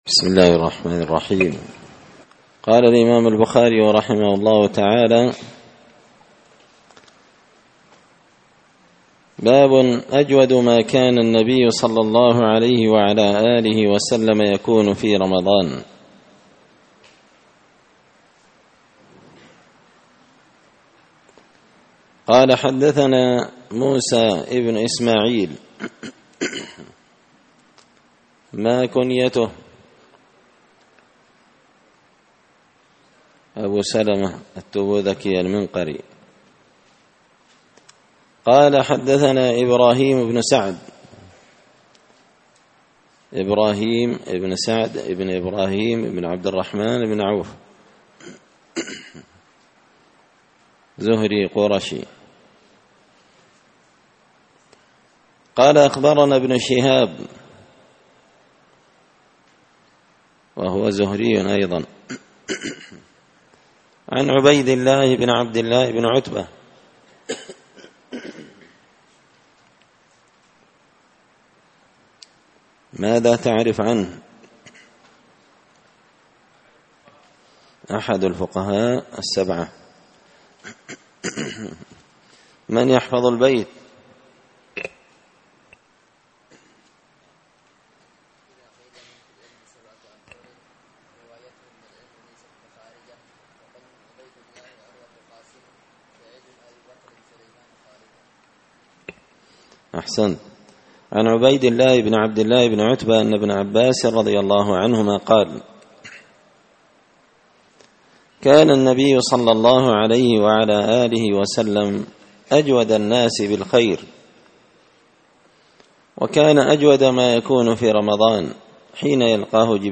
كتاب الصيام من صحيح البخاري الدرس السابع (7) باب أجود ماكان النبي صلى الله عليه وسلم يكون في رمضان